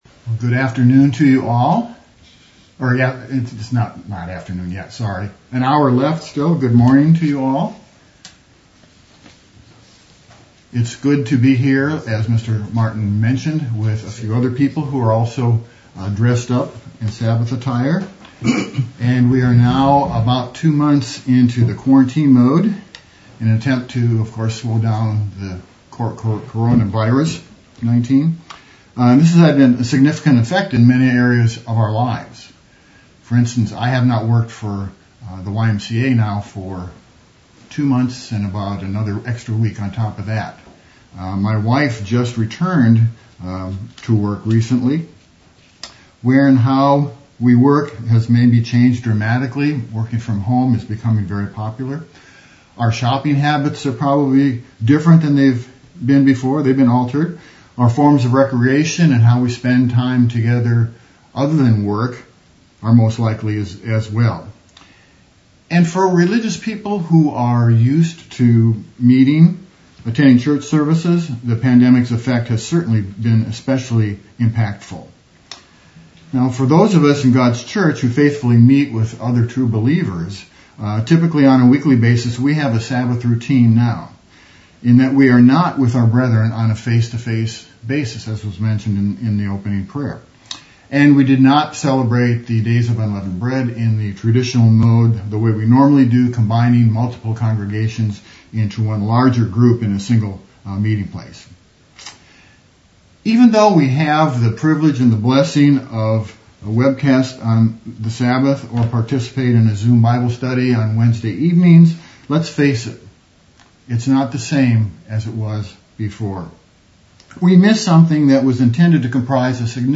In this sermon we'll delve into the subject of fellowship. We'll define what fellowship is and give some characteristics of it. We'll come to a deeper understanding of it importance of fellowship as a part of the Christian's life and how we incorporate fellowship into it.